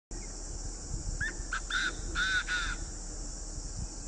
中华鹧鸪鸟叫声